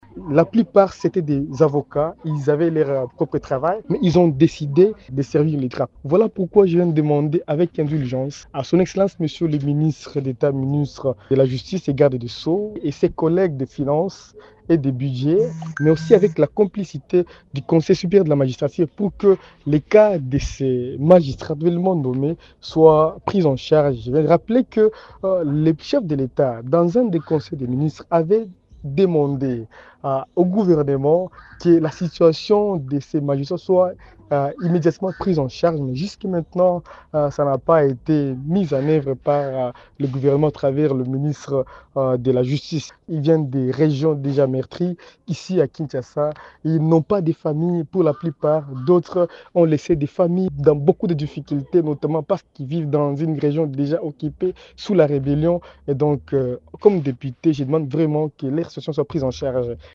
Ecoutez le député provincial :